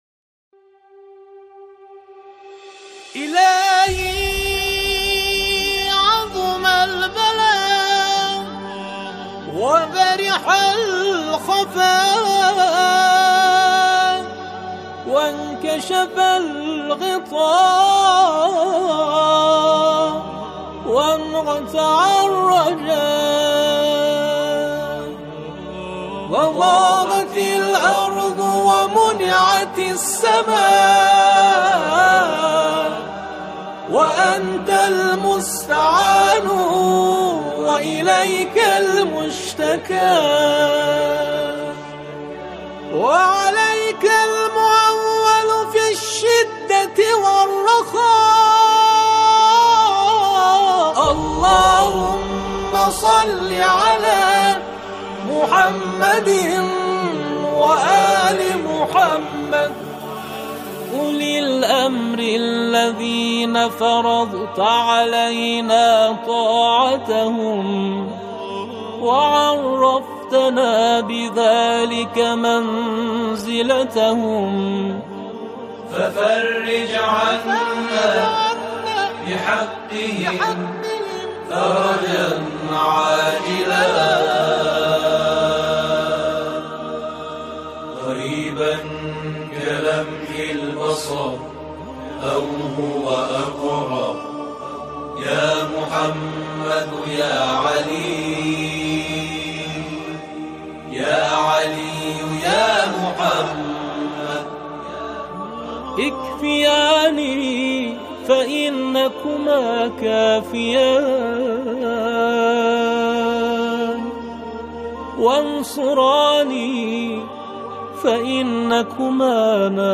آکاپلا
در این قطعه، اعضای گروه،  مناجاتی را همخوانی می‌کنند.